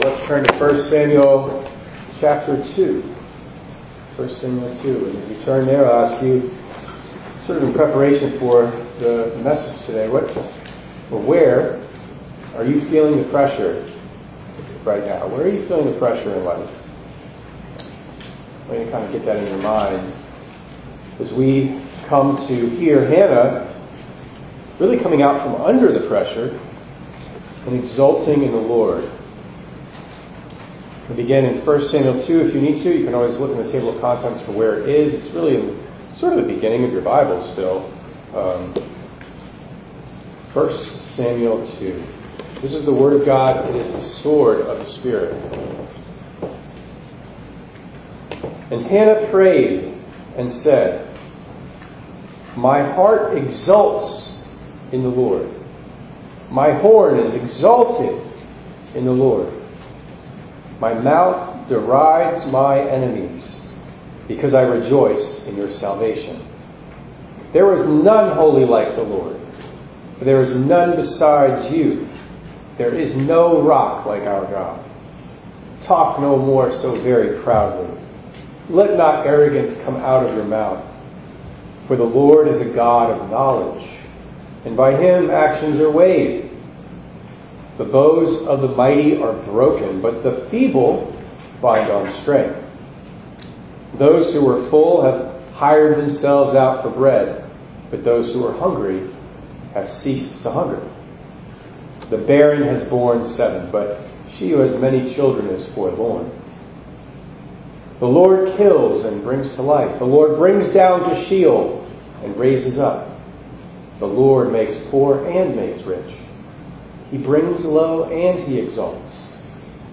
7_21_24_ENG_Sermon.mp3